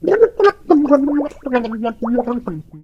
otis_start_vo_04.ogg